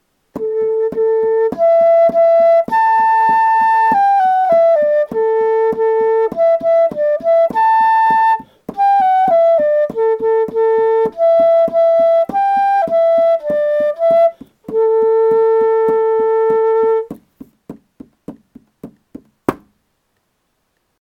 Chant Melody audio (no words)
heimdall_chant.mp3